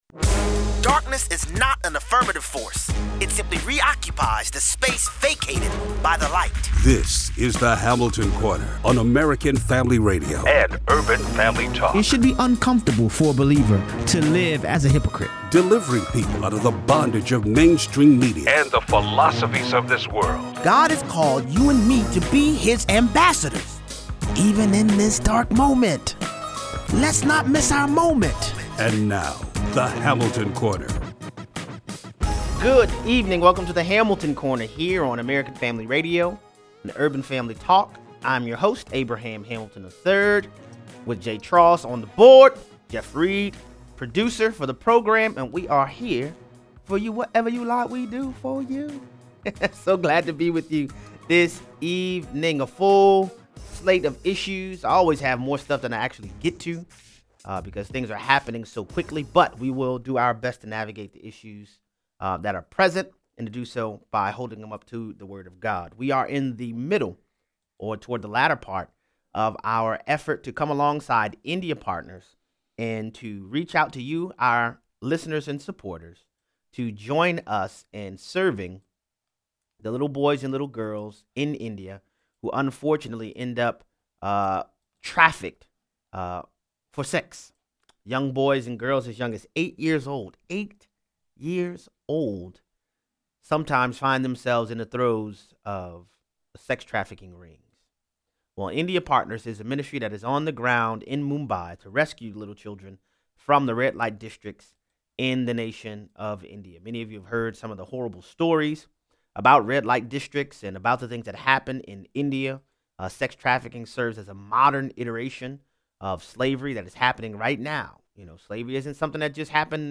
Callers weigh in